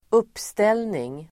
Uttal: [²'up:stel:ning]